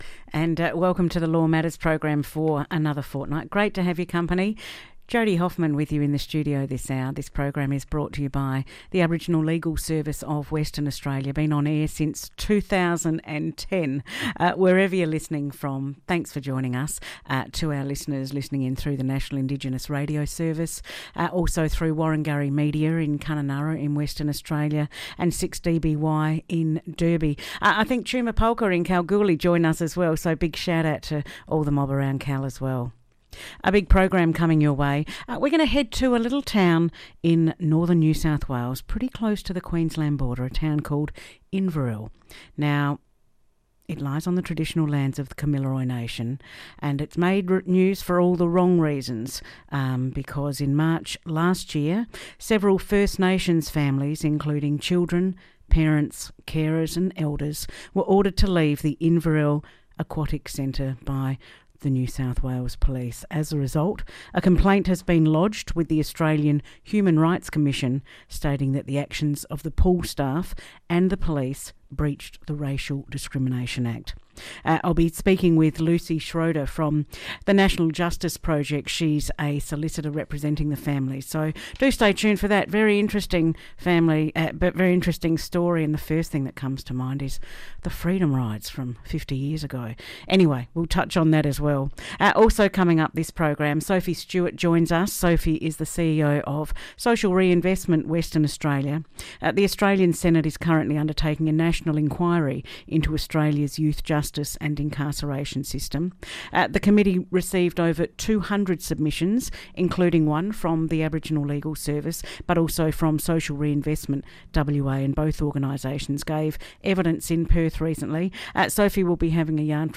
Guests: Dr Jacqueline McGowan-Jones – WA Commissioner for Children and Young People RE: 2026 Profile of Children and Young People in WA.
Radio Programs